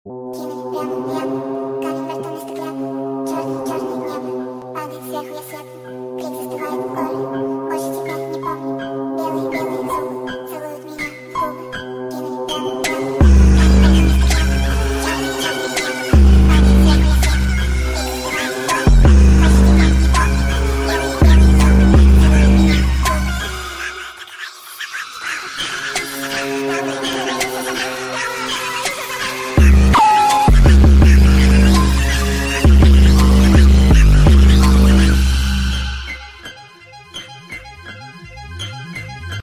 Speed Up ) [ Audio edit ]